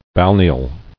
[bal·ne·al]